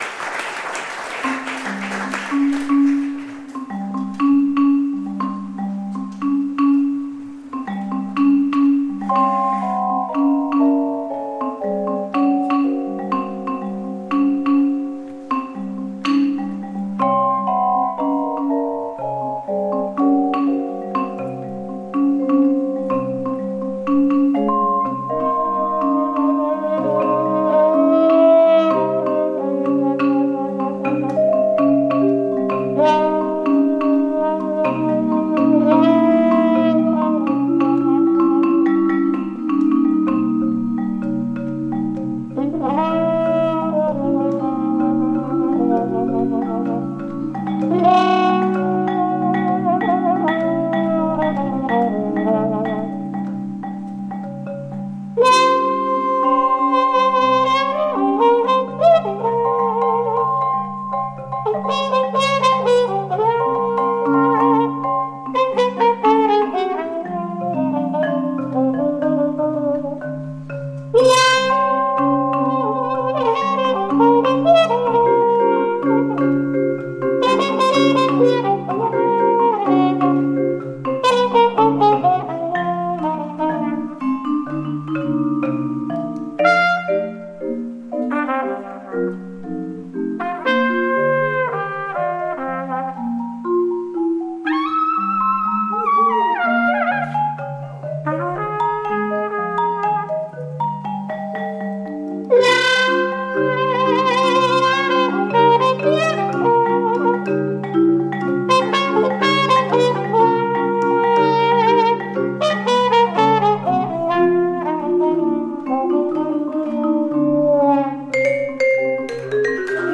4-jazz-masters-trio.mp3